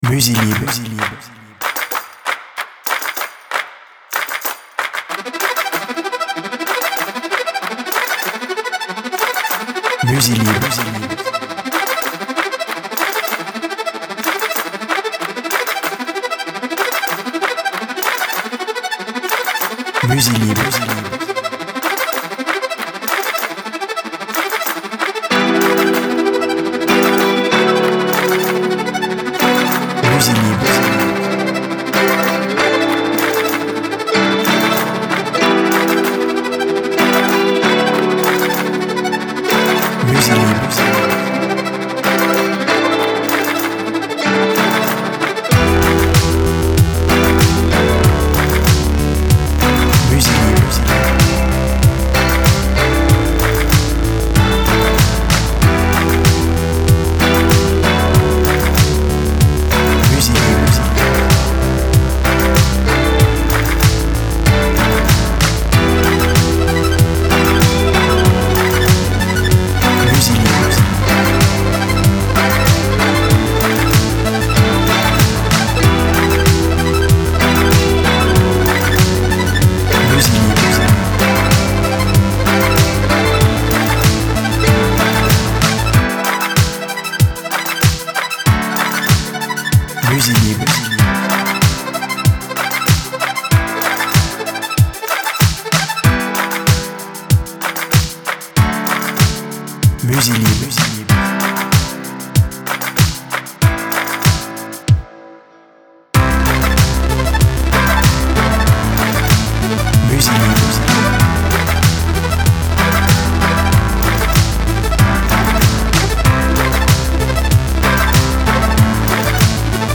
Musiques électroniques